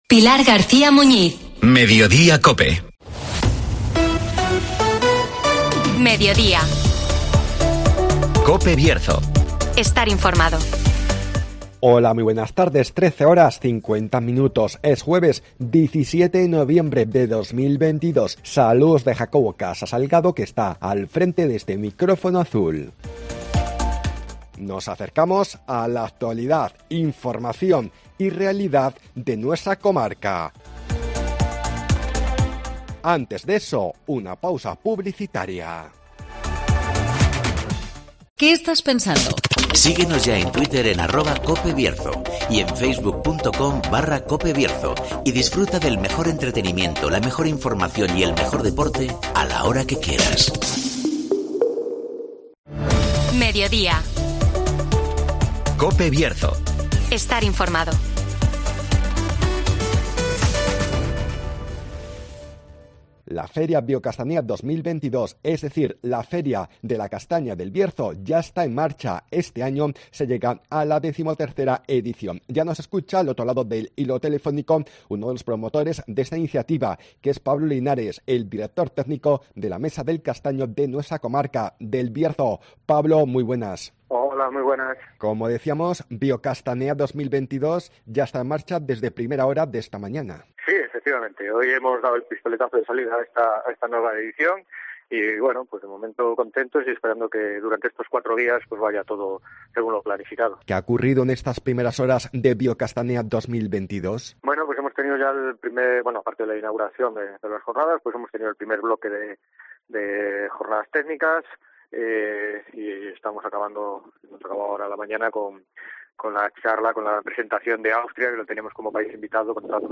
La feria BioCastanea 2022 ya está en marcha (Entrevista